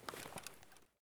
sounds / weapons / rattle / lower / lower_6.ogg